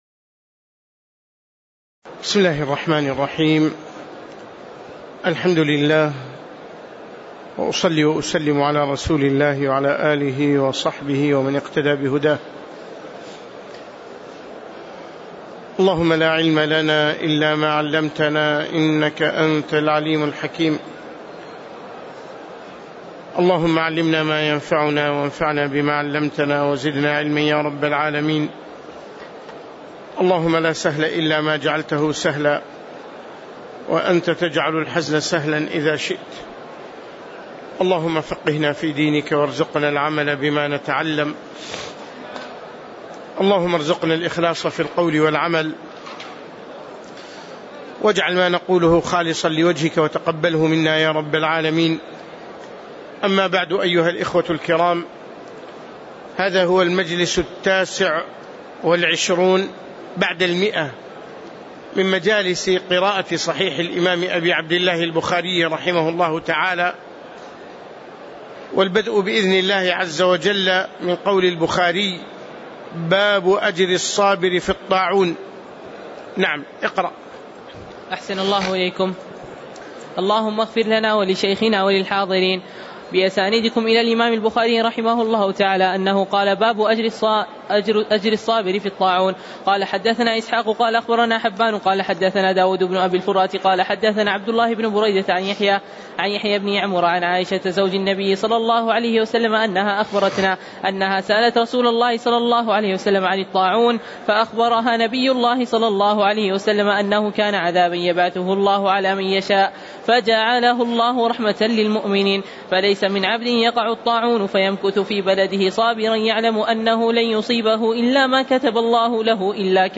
تاريخ النشر ٣ رمضان ١٤٣٨ هـ المكان: المسجد النبوي الشيخ